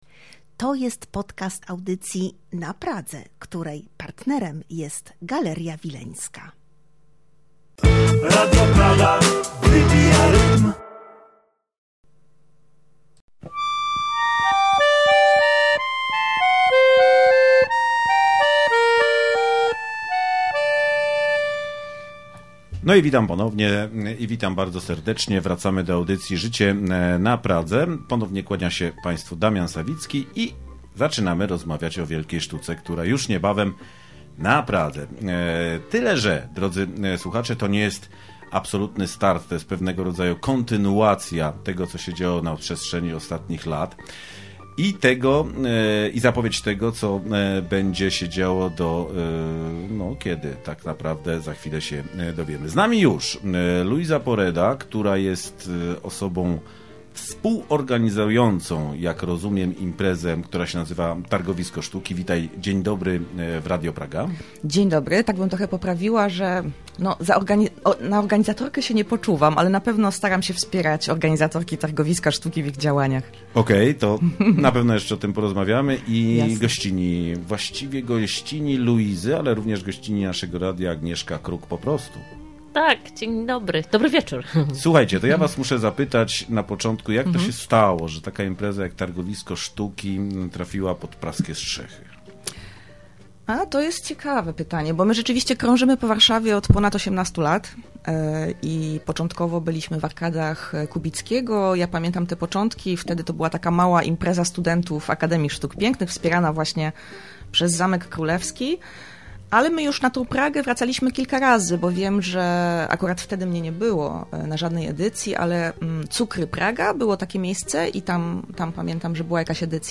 PODCAST! - RADIO PRAGA